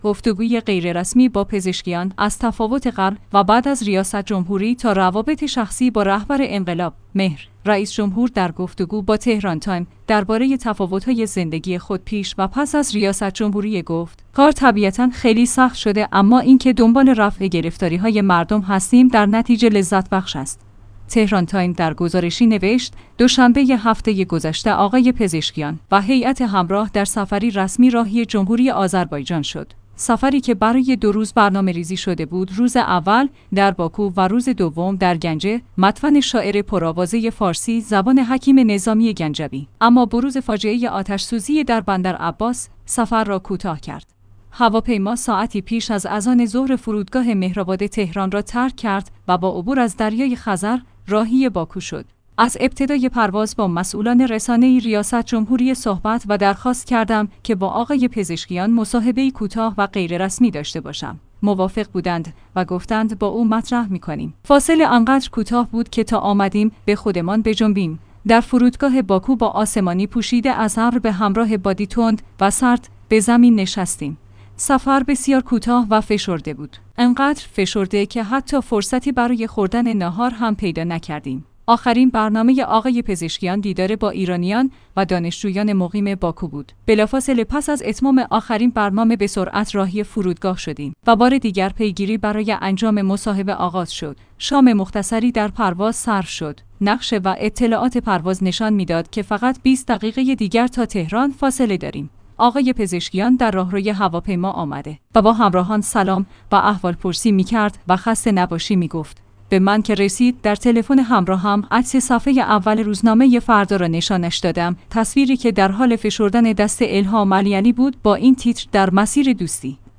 گفتگوی غیررسمی با پزشکیان؛ از تفاوت قبل و بعد از ریاست جمهوری تا روابط شخصی با رهبر انقلاب